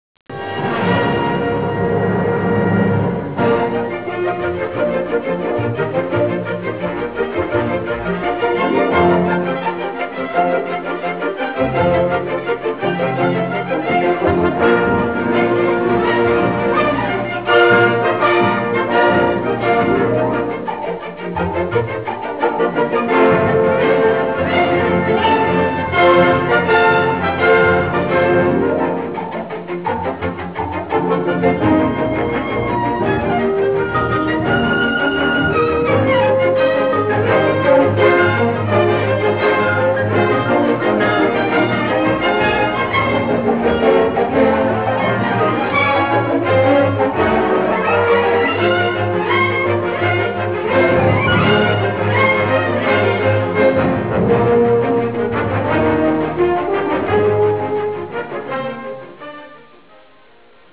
suggestiva colonna musicale
Track Music